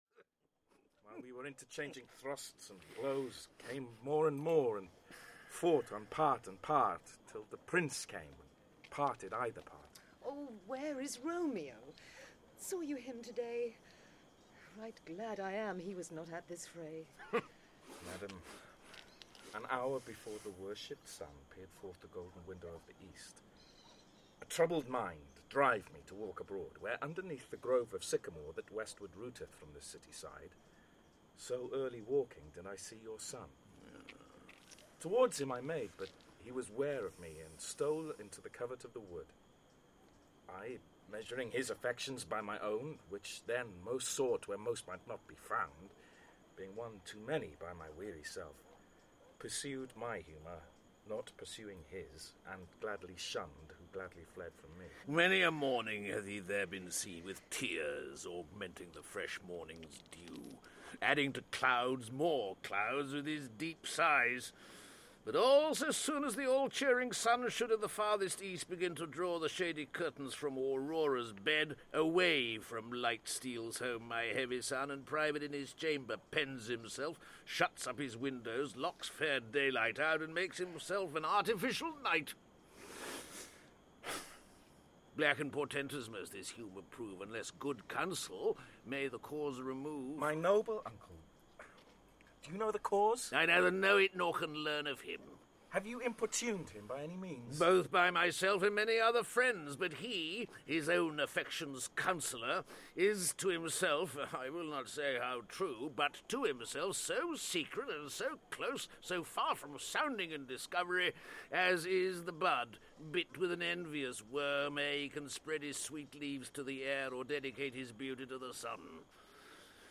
Romeo and Juliet (EN) audiokniha
Ukázka z knihy